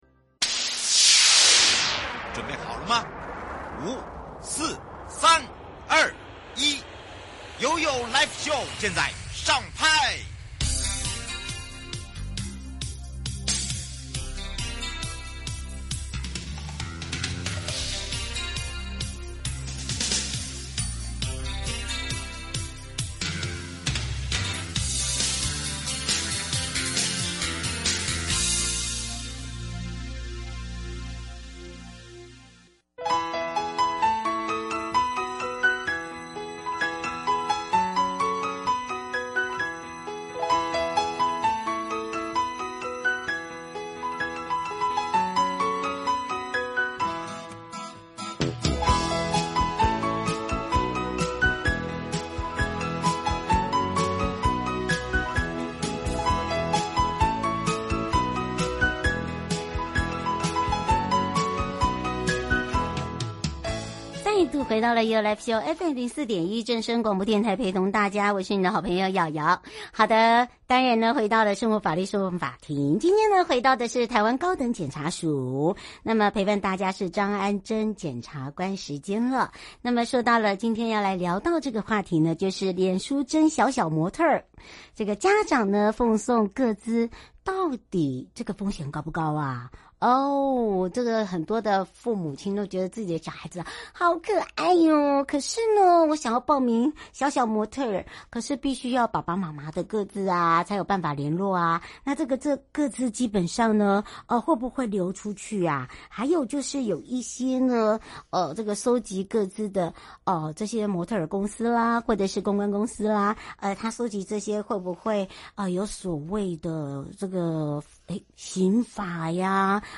受訪者： 1. 台灣高等檢察署張安箴檢察官 2. 臺灣高等檢察署張斗輝檢察長 節目內容： 1.